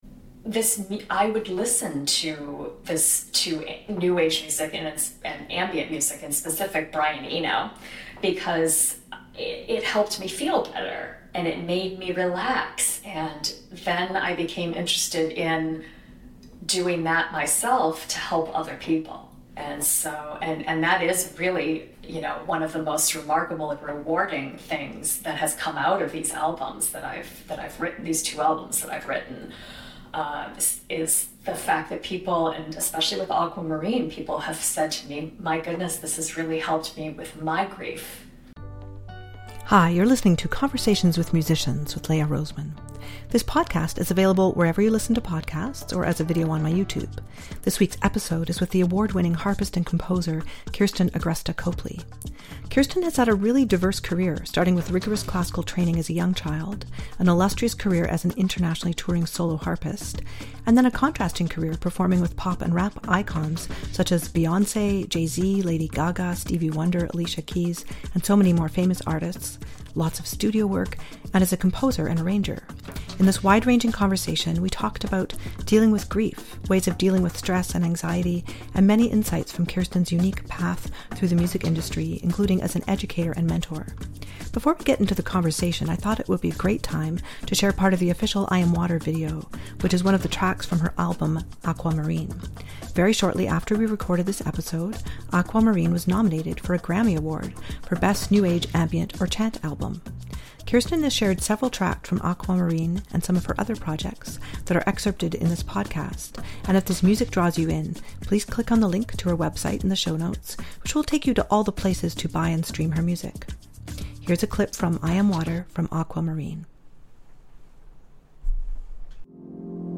multi-style Harpist, Composer, Arranger